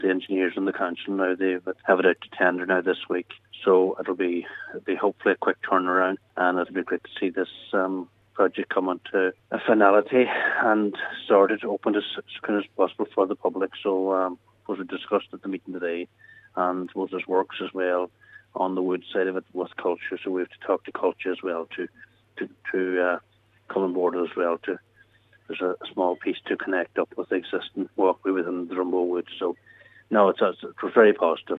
Cllr Martin Harley said it is the news they have been waiting to hear: